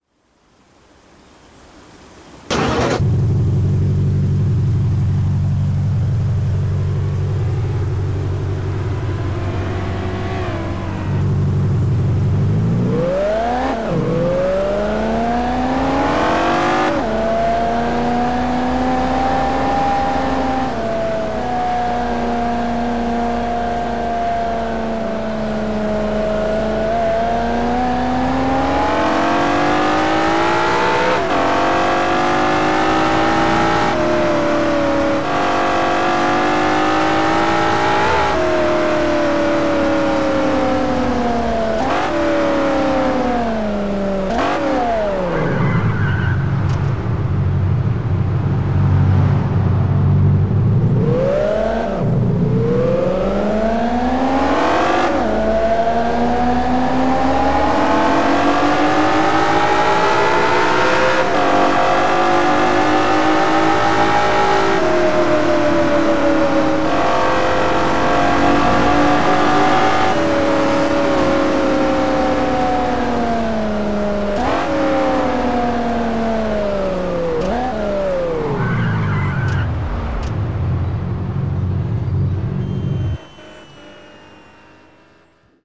- BMW M3 [E92] [EDM V8]